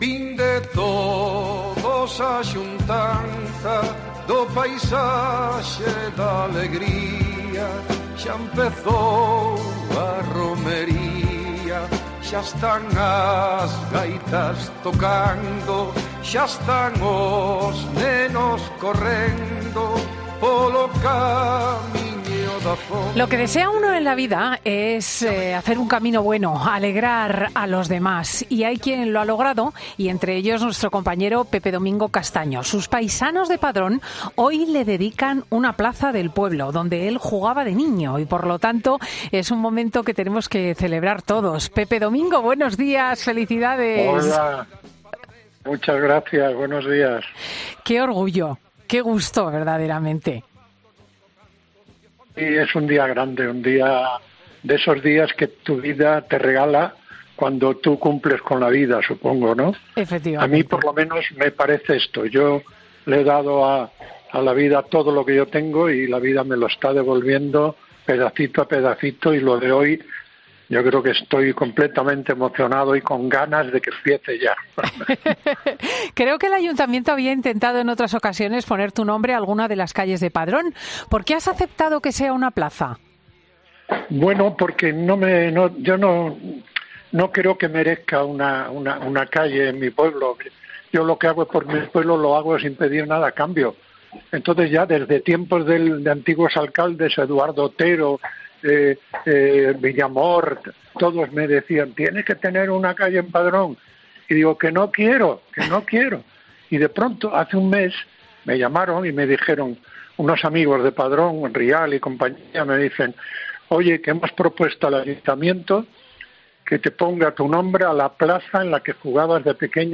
El comunicador de 'Tiempo de Juego' ha explicado en 'Fin de Semana' cómo se siente tras la decisión de su pueblo de ponerle a una plaza su nombre
"Iremos al cementerio, porque una buena manera de agradecer a mis padres es dedicarles un ramo de flores como homenaje por lo que va a pasar hoy" nos contaba con mucha emoción en su voz.